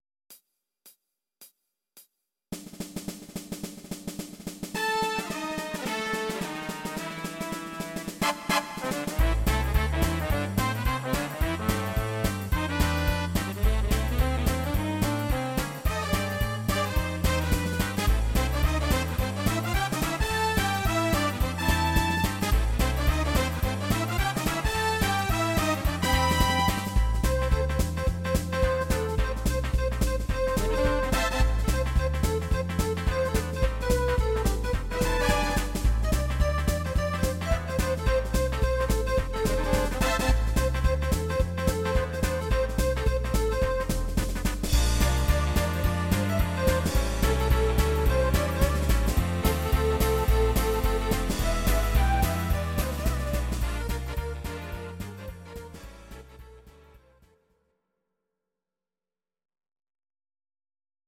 Audio Recordings based on Midi-files
Pop, Rock, 1990s